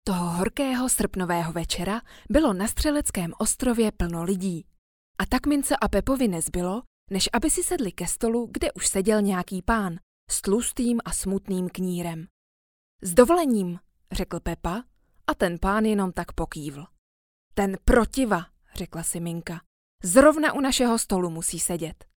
Profesionální český ženský voiceover
profesionální zvuk a vysoká technická kvalita
ukazka-povidka.mp3